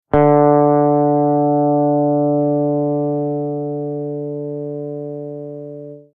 Simply click the play button to get the sound of the note for each string (E, A, D, G, B and E).
D String
d-note.mp3